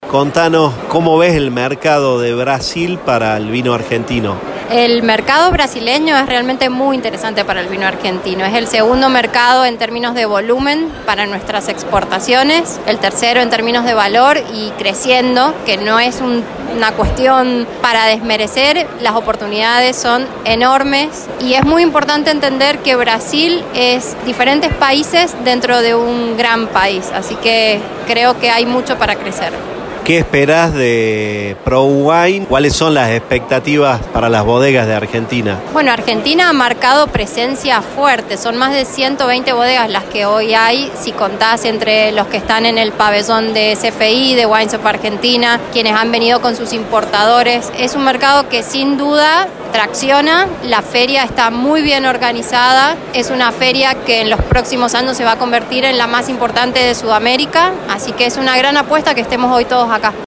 Entrevistamos
Que se esta desarrollando en San Pablo en la Expo Center Norte.